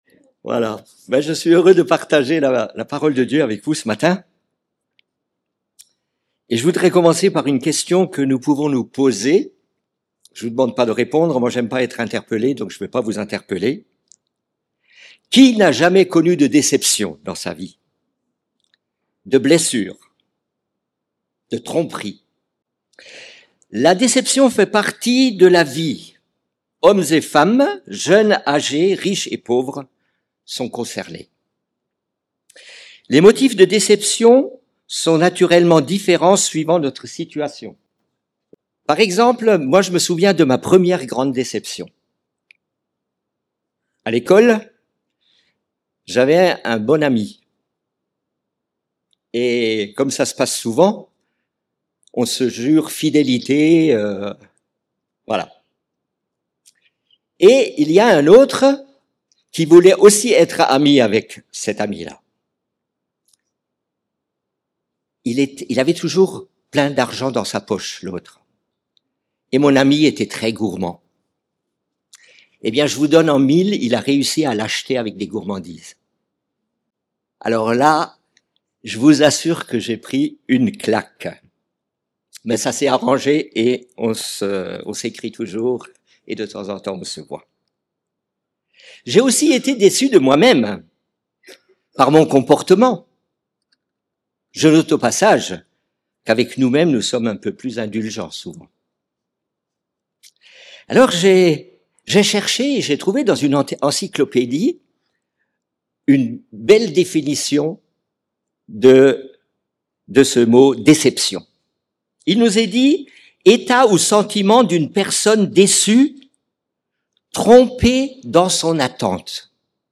SUMMARY:Culte hebdomadaire